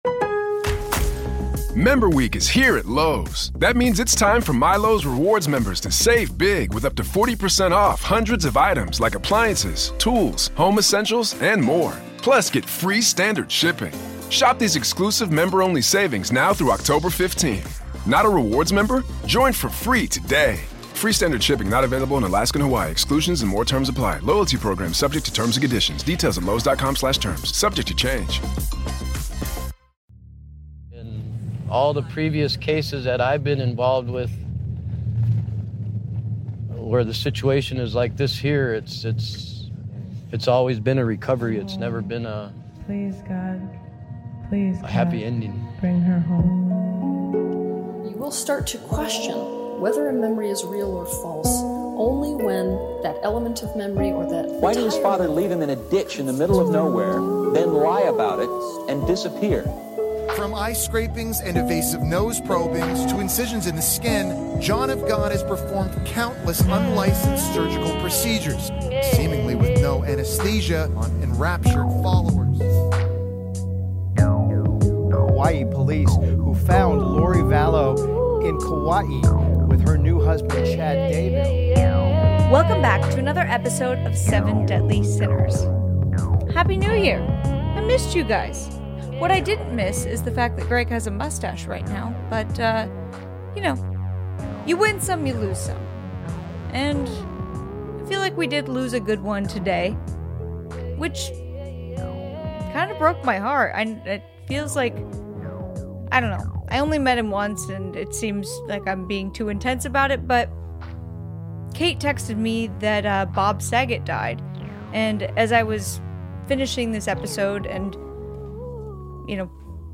Lori Vallow and Chad Daybell's doomsday inspired crimes. Episode includes exclusive interviews with two people close to the case